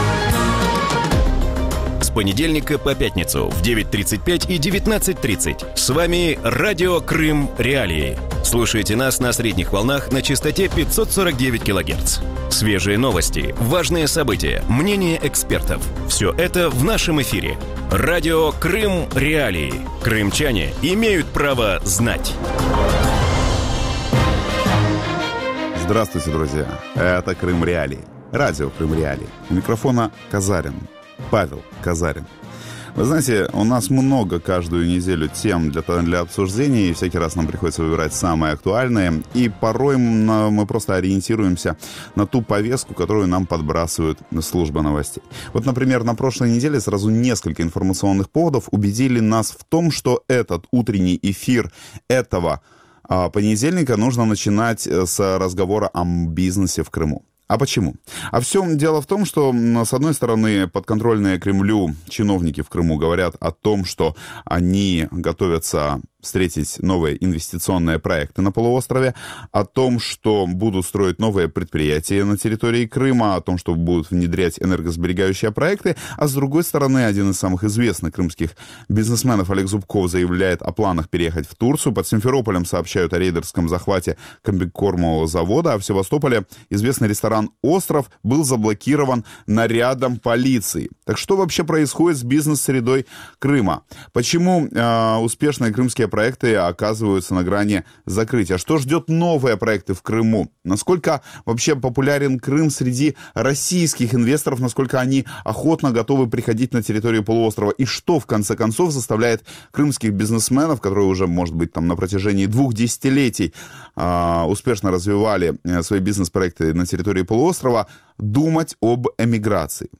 В утреннем эфире Радио Крым.Реалии говорят о бизнес-климате в Крыму.